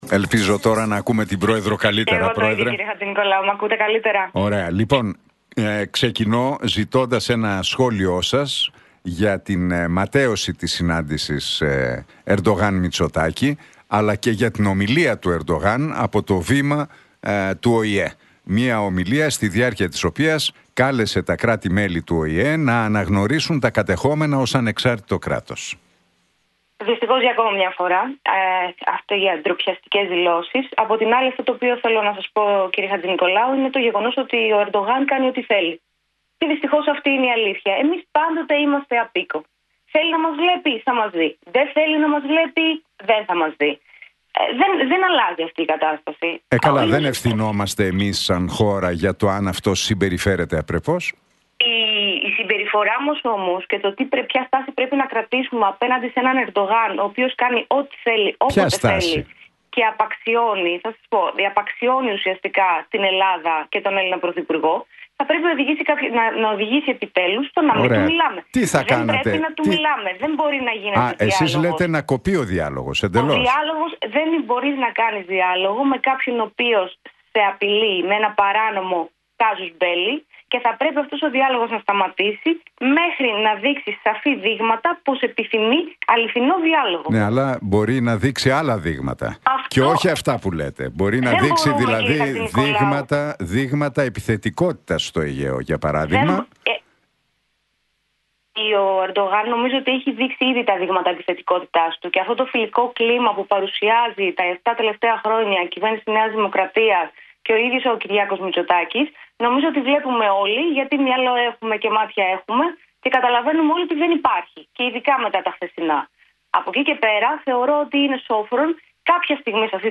Η Αφροδίτη Λατινοπούλου, πρόεδρος της Φωνής Λογικής, σε συνέντευξή της στον Realfm 97,8, εξέφρασε την άποψη ότι η Ελλάδα πρέπει να διακόψει τον διάλογο με την
Για τα ελληνοτουρκικά και την ακύρωση της συνάντησης Μητσοτάκη – Ερντογάν και το μεταναστευτικό μίλησε η πρόεδρος της Φωνής Λογικής, Αφροδίτη Λατινοπούλου στον Νίκο Χατζηνικολάου από την συχνότητα του Realfm 97,8.